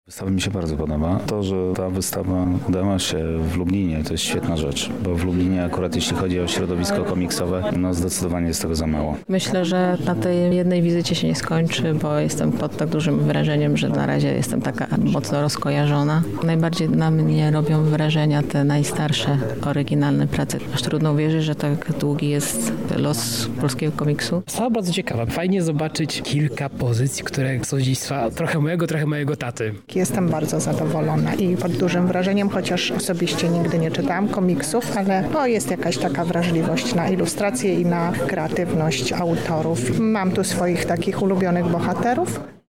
O wrażenia zapytaliśmy również odwiedzających wernisaż: